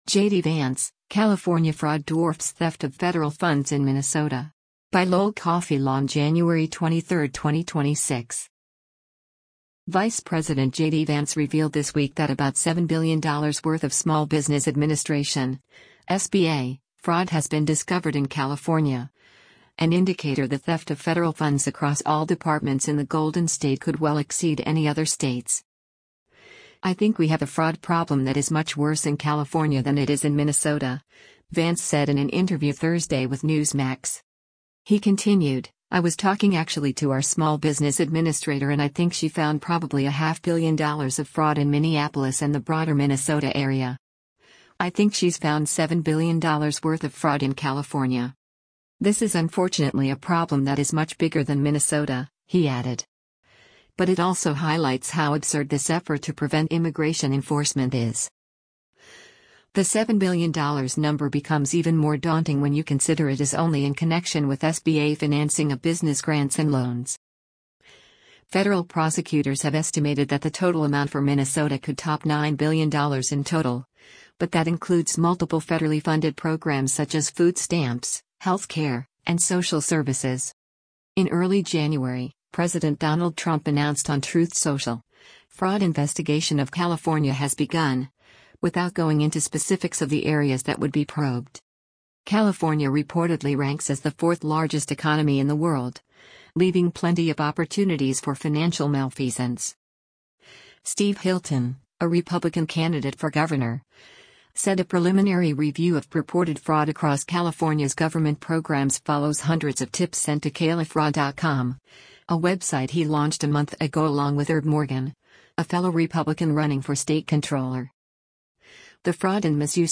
“I think we have a fraud problem that is much worse in California than it is in Minnesota,” Vance said in an interview Thursday with Newsmax.